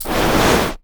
SFX / Auras / Burning / Burnout